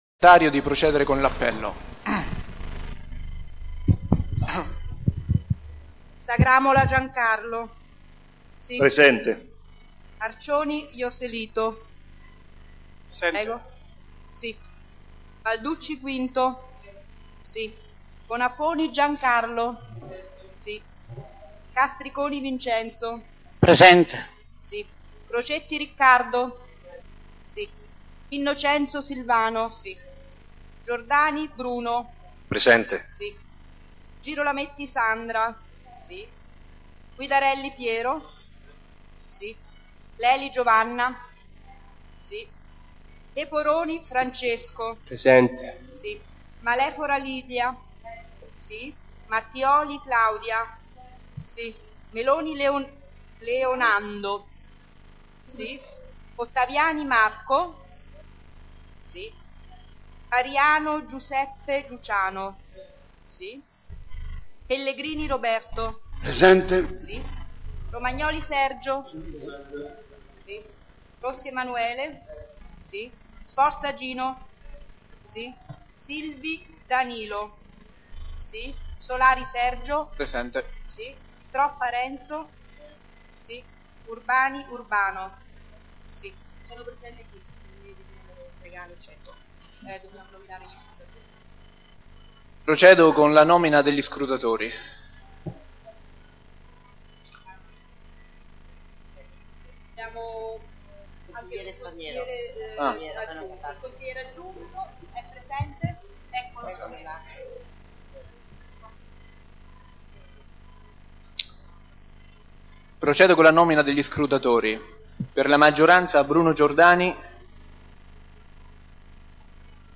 Seduta di sabato 09 giugno 2012
il Consiglio Comunale e` convocato presso Palazzo Chiavelli - sala consiliare sabato 9 giugno 2012 ore 16.00